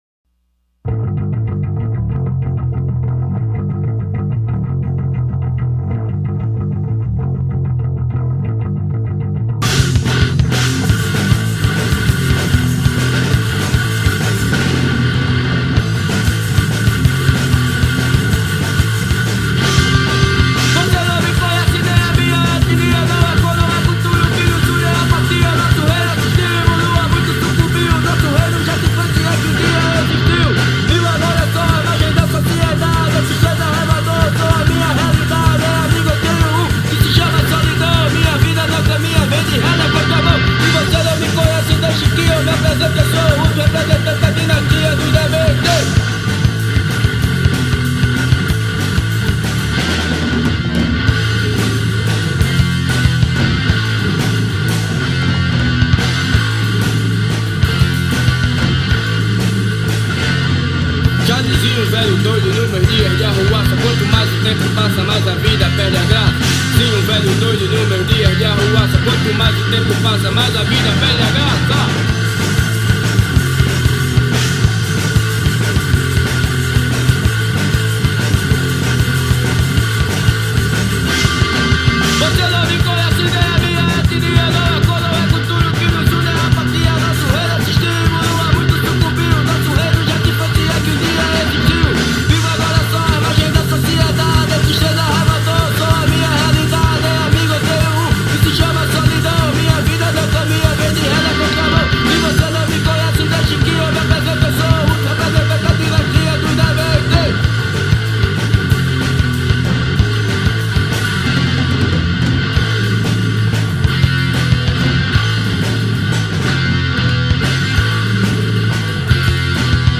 Som direto, sem excesso, com peso e intenção.